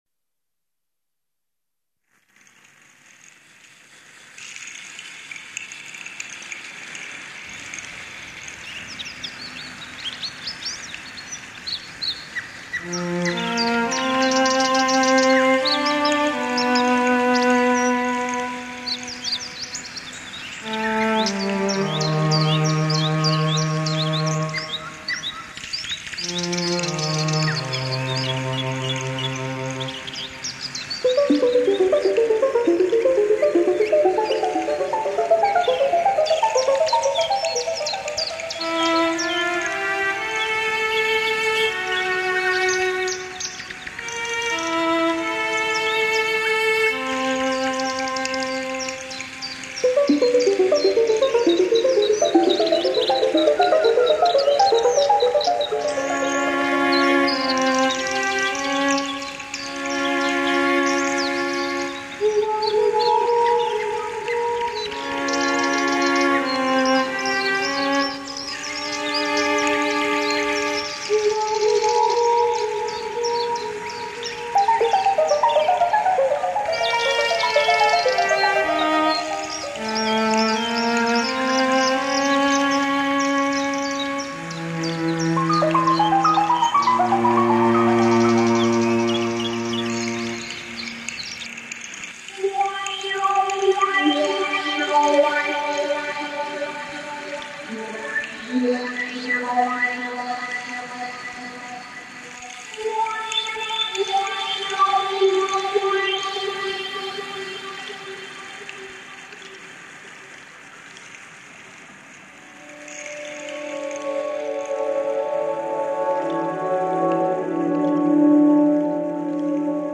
AMBIENT MUSIC